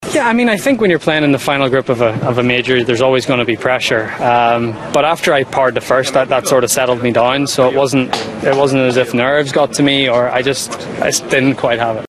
McIlroy says he just didn’t play well enough when it mattered…